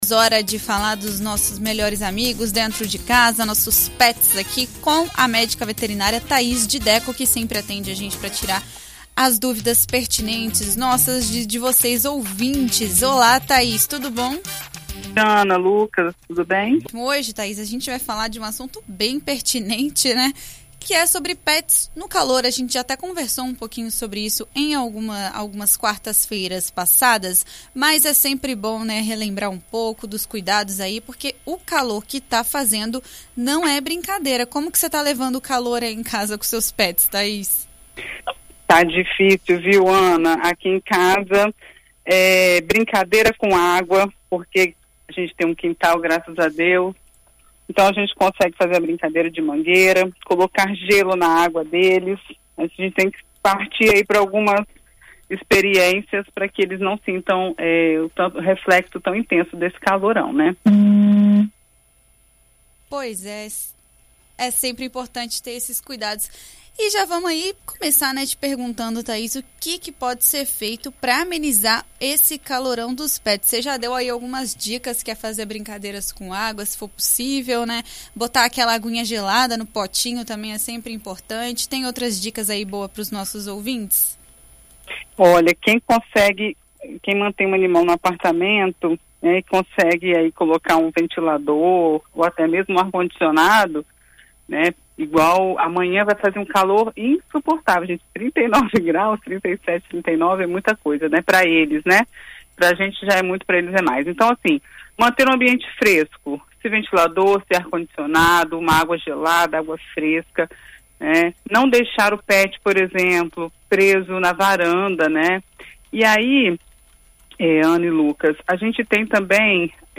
Em entrevista à BandNews FM ES nesta quarta-feira